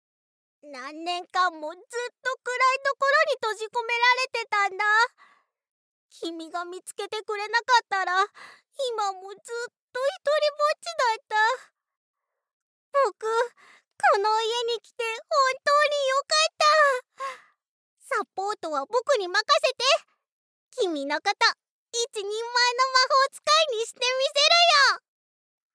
ボイスサンプル
マスコット